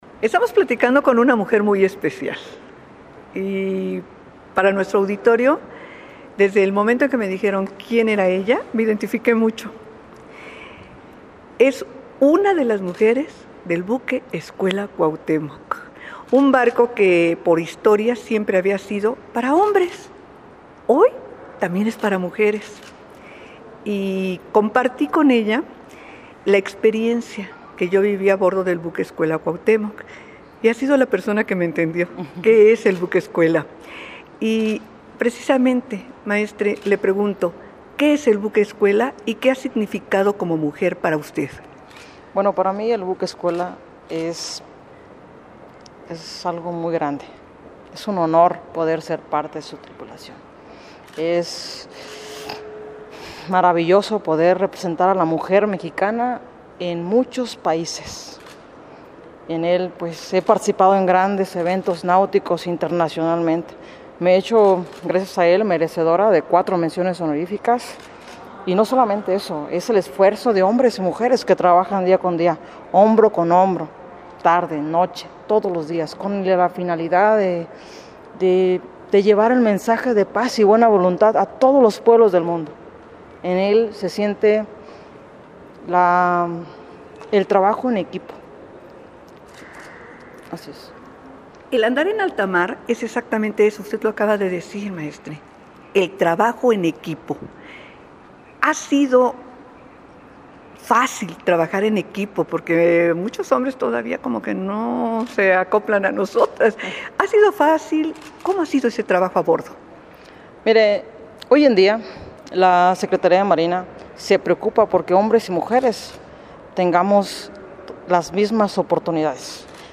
Entrevista
ENTREVISTA-COMPLETA-MUJER-BUQUE-CUAU.mp3